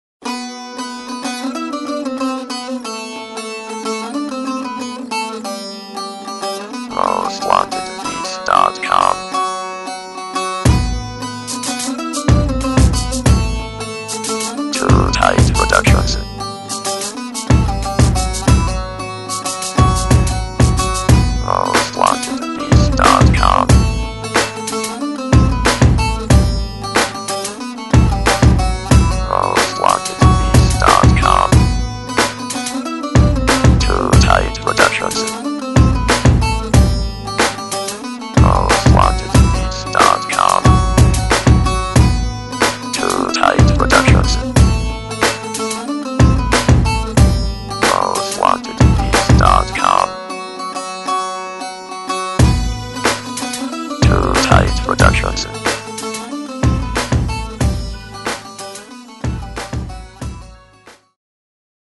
ETHNIC CLUB HIP HOP BEAT